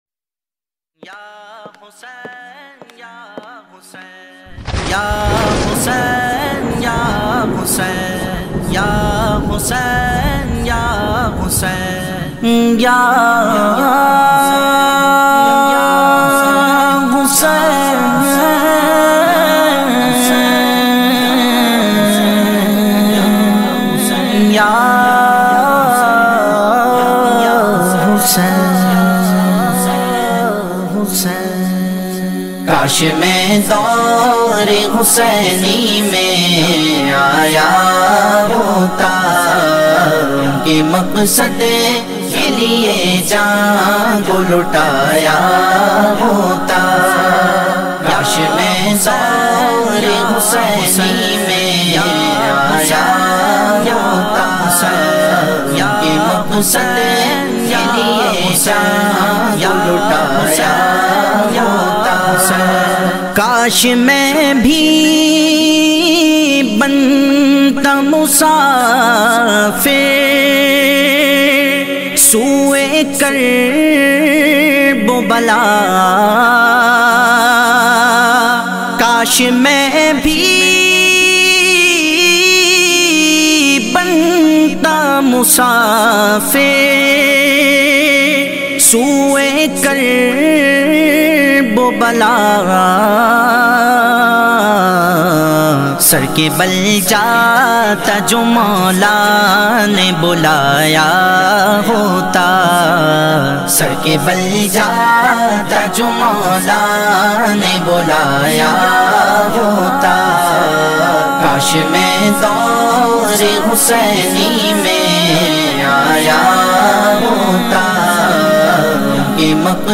New Muharram Naat